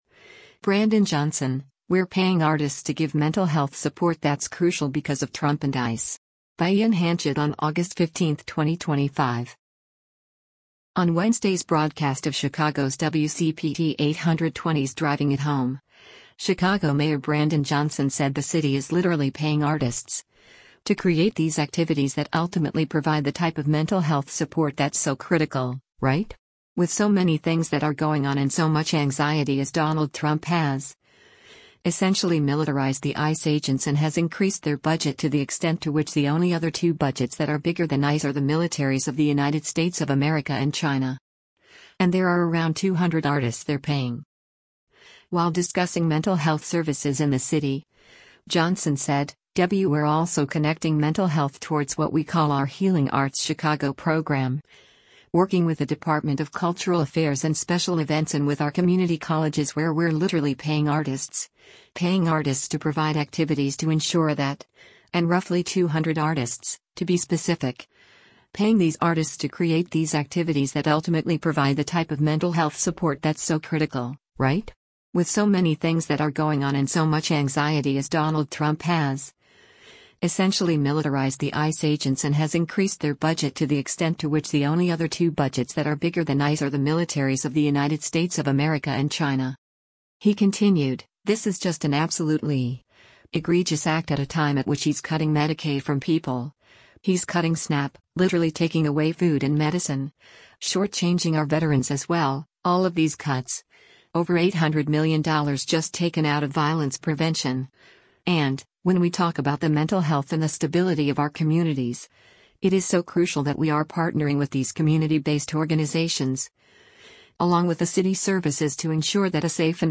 On Wednesday’s broadcast of Chicago’s WCPT 820’s “Driving it Home,” Chicago Mayor Brandon Johnson said the city is “literally paying artists,” “to create these activities that ultimately provide the type of mental health support that’s so critical, right?